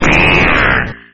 Archivo:Grito de Ekans.ogg